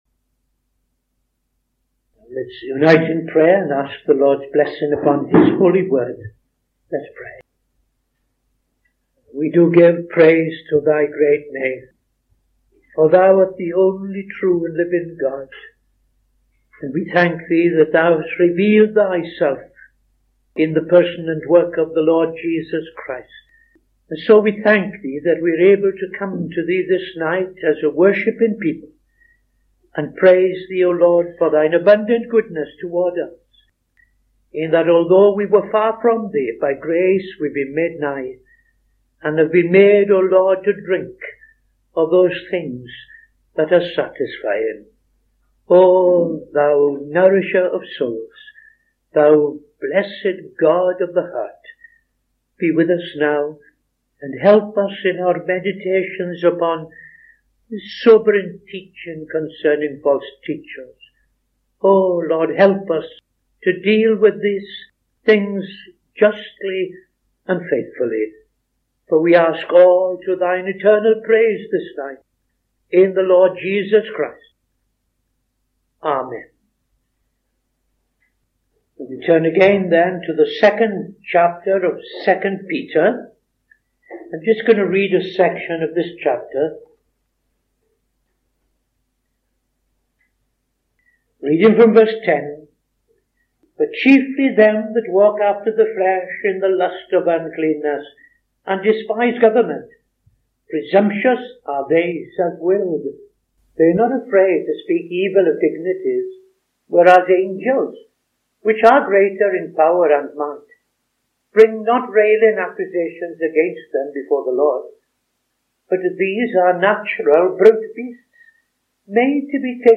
Opening Prayer and Reading II Peter 2:9-16